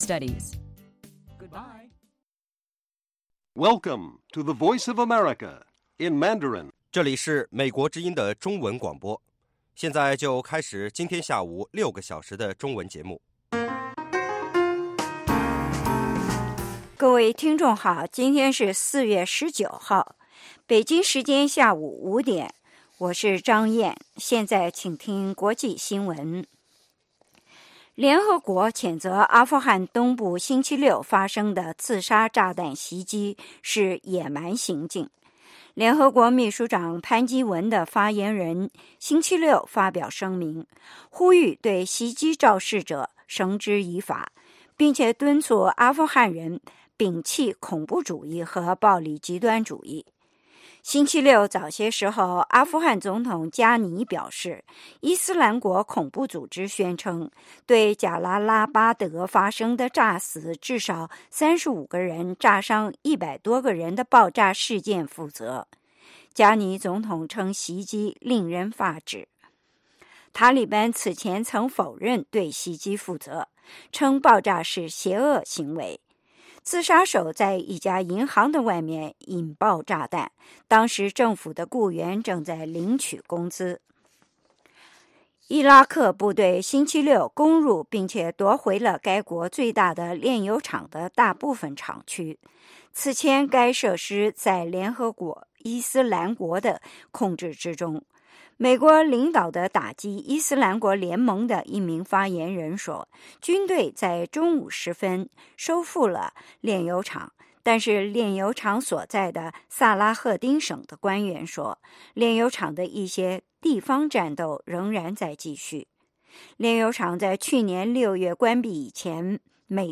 国际新闻 英语教学